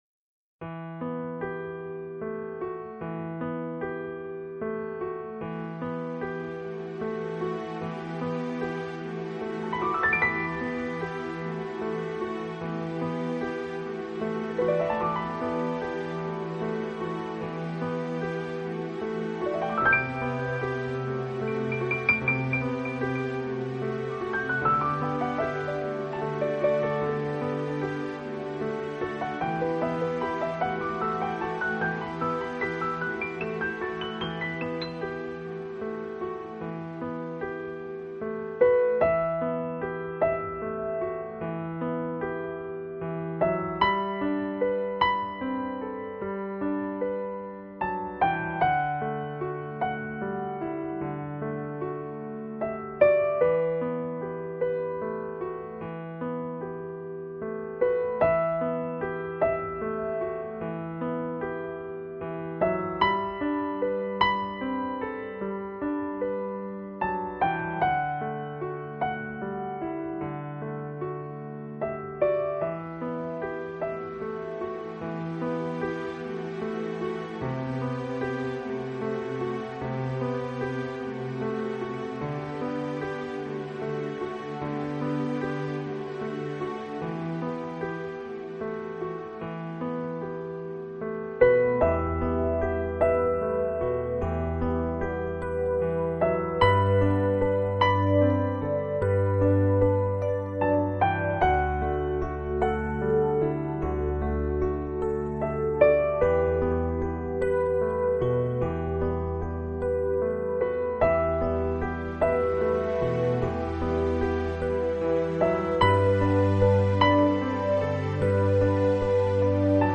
音樂成份：特別添加催淚的深情節奏、促進悲傷循環的旋律、溫柔撫慰的療傷音
聆聽音樂，讓心在鋼琴的柔情共振中，一點一滴的釋放心中的悲傷、憂愁與恐懼，隨著樂曲的引導，回到平靜、清透